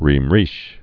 (rēm rēsh)